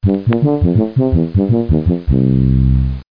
00075_Sound_gameOver.mp3